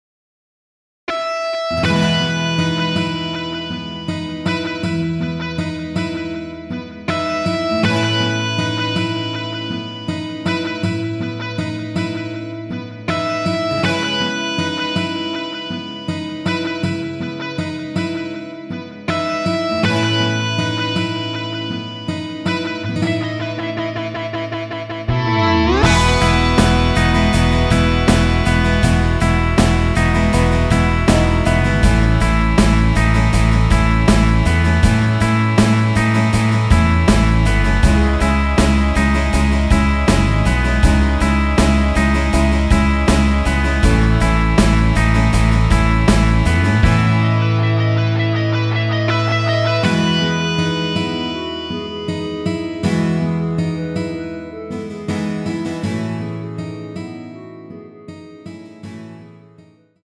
원키 멜로디 포함된 MR입니다.
멜로디 MR이란
앞부분30초, 뒷부분30초씩 편집해서 올려 드리고 있습니다.
중간에 음이 끈어지고 다시 나오는 이유는